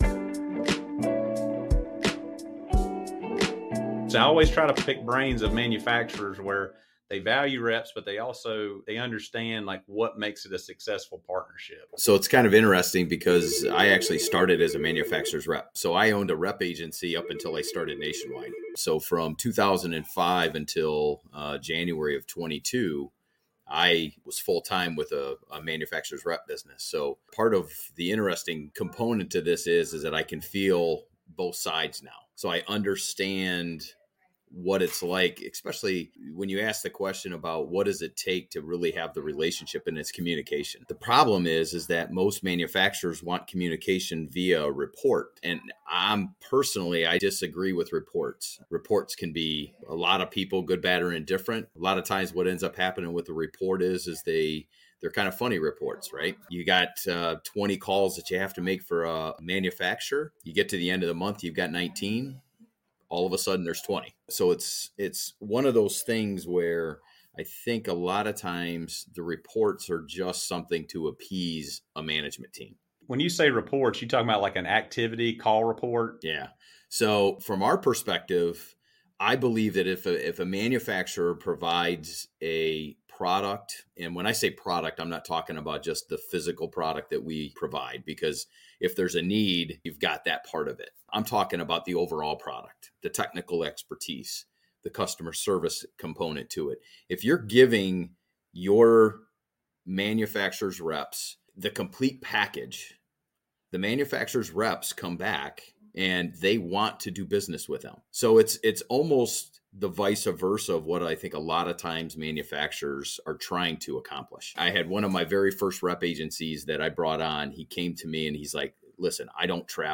In this episode of 'Flowing Sales,' we dive deep into the critical components of a successful partnership between manufacturers and their reps. Our guest